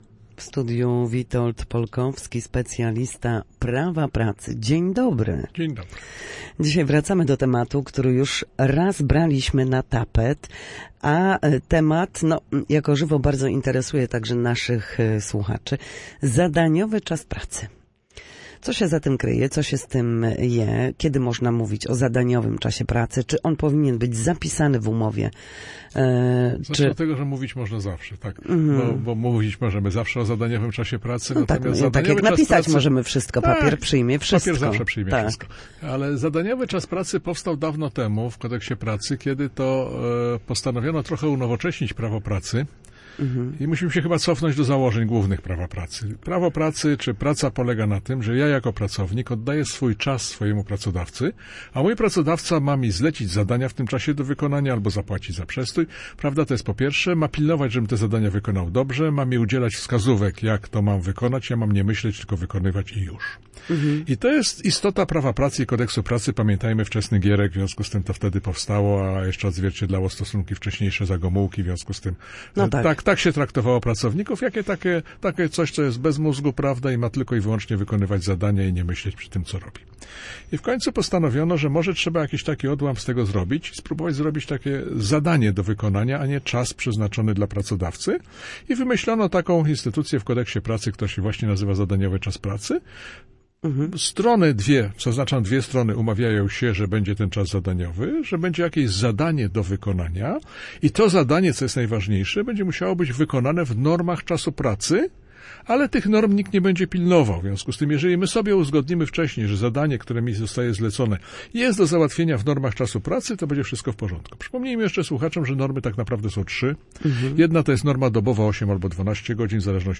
W każdy wtorek po godzinie 13:00 na antenie Studia Słupsk przybliżamy Państwu zagadnienia dotyczące prawa pracy.
odpowiada na pytania słuchaczy, komentuje zmiany w prawie pracy oraz przybliża znajomość przepisów.